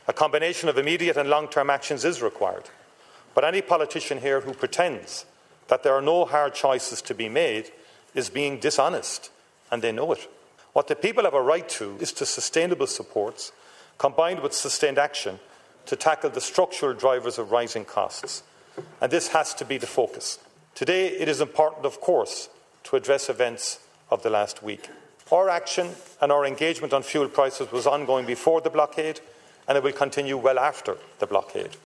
When the Order of Business was passed, Taoiseach Micheal Martin moved the counter motion expressing confidence in the government.